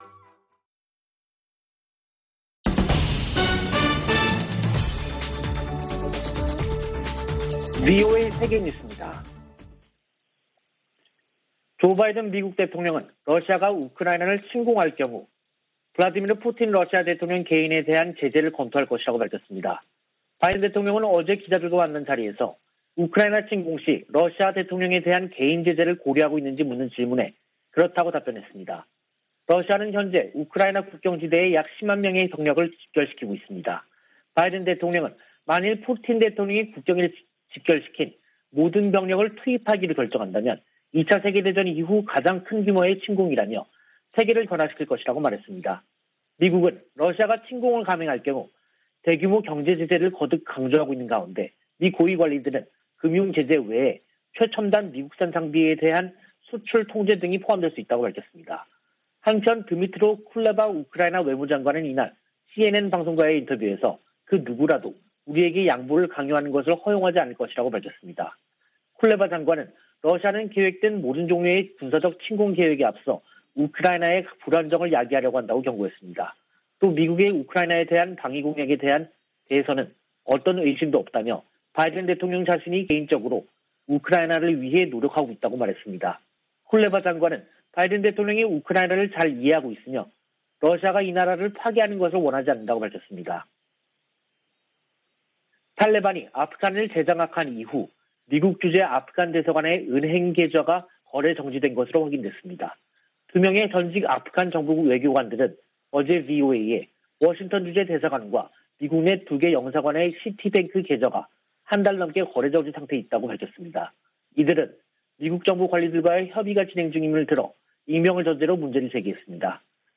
VOA 한국어 간판 뉴스 프로그램 '뉴스 투데이', 2022년 1월 26일 3부 방송입니다. 미 국무부는 북한이 순항미사일 2발을 발사한 것과 관련, 여전히 평가 중이라고 밝히고, 대북 정책 목표는 여전히 한반도의 완전한 비핵화라고 확인했습니다. 북한의 잇따른 미사일 발사는 평화와 안정을 위협한다고 백악관 국가안보회의 부보좌관이 밝혔습니다. 유엔은 북한이 올해 5번째 무력 시위를 강행한데 대해 한반도 긴장 완화를 위한 대화를 촉구했습니다.